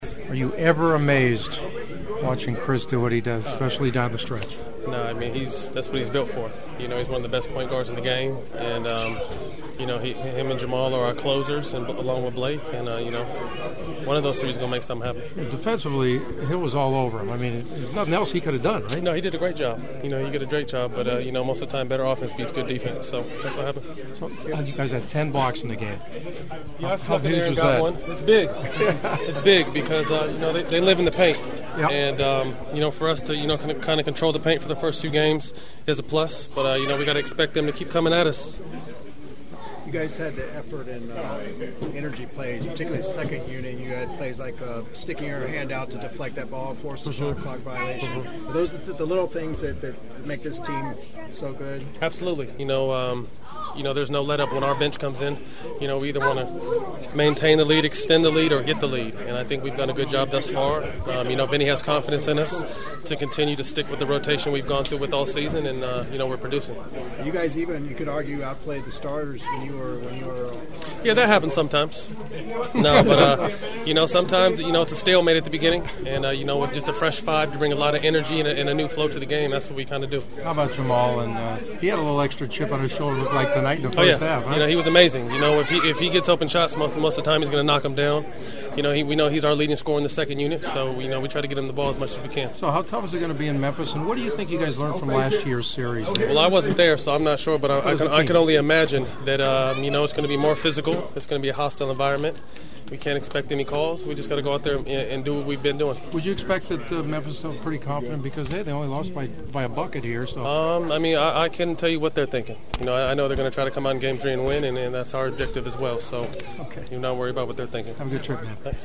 The following are my postgame Clipper interviews along with several preview thoughts for games 3 and 4 in Memphis on Thursday and Saturday (which you can hear of course on KFWB Newstalk 980).